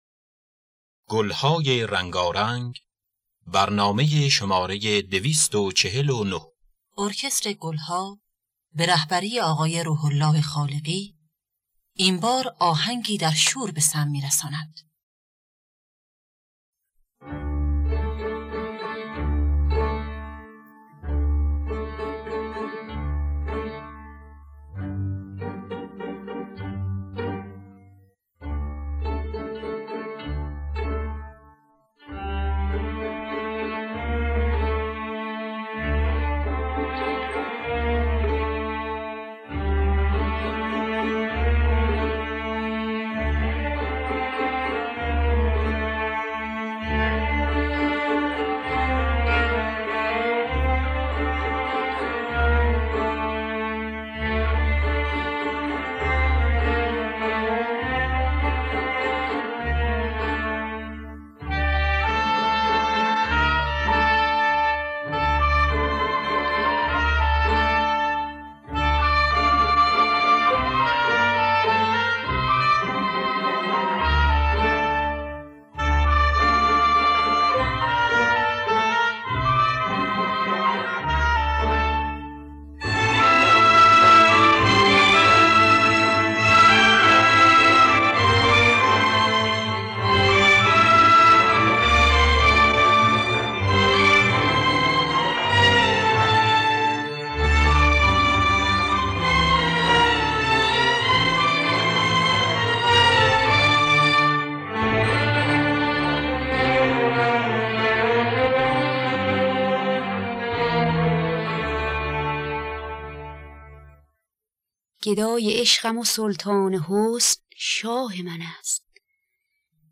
خوانندگان: بنان نوازندگان: مرتضی محجوبی جواد معروفی روح‌الله خالقی